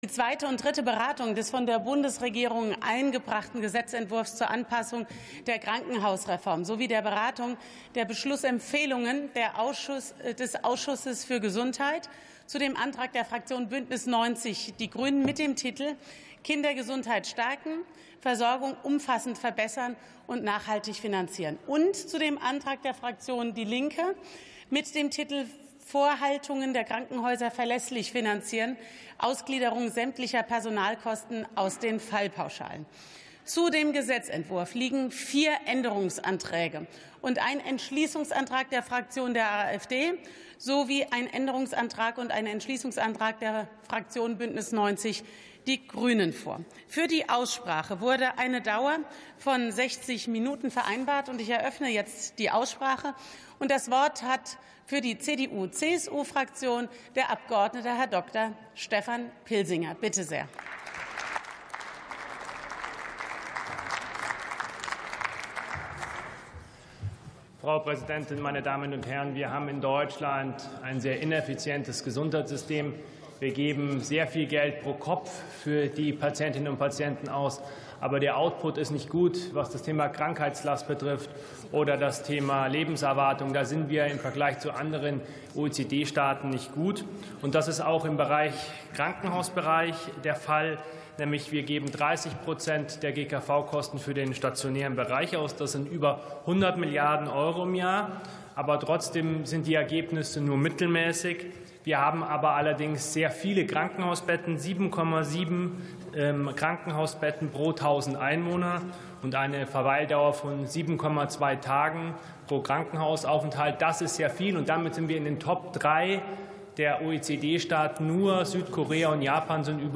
63. Sitzung vom 06.03.2026. TOP ZP 7-9: Krankenhausreform ~ Plenarsitzungen - Audio Podcasts Podcast